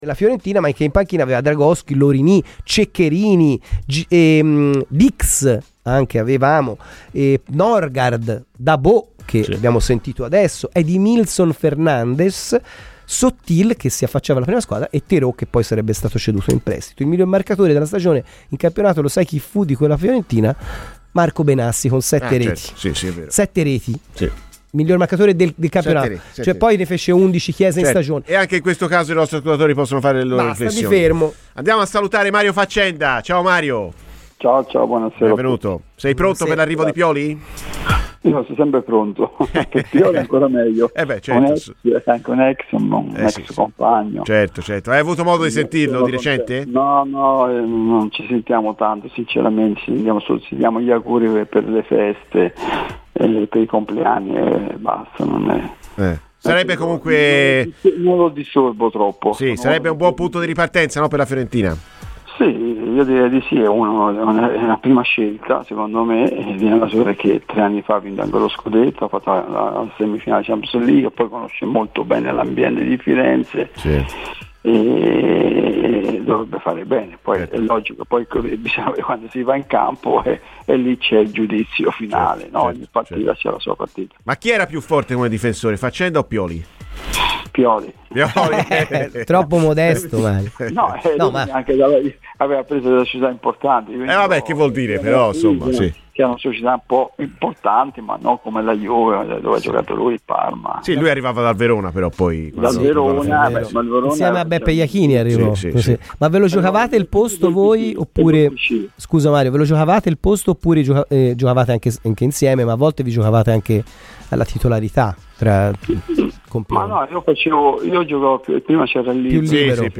Ascolta il podcast per l'intervista integrale.